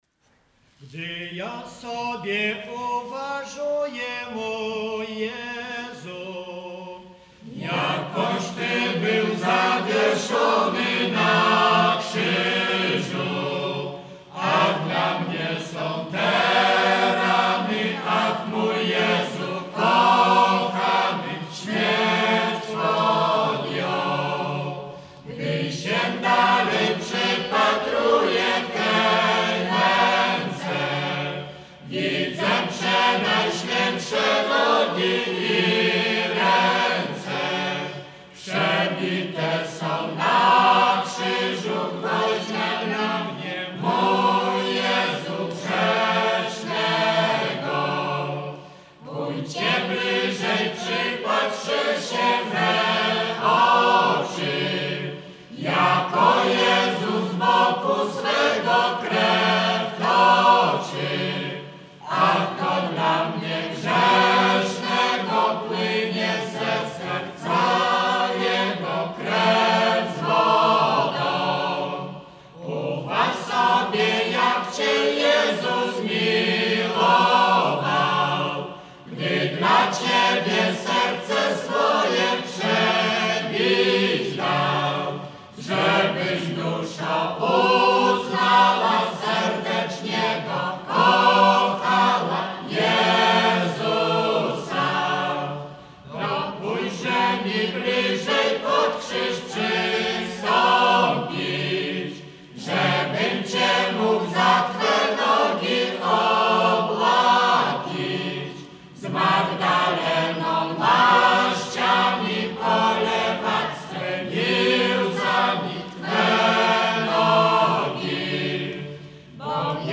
Zespół "Pogranicze" z Szypliszk
Suwalszczyzna
Wielkopostna
katolickie nabożne wielkopostne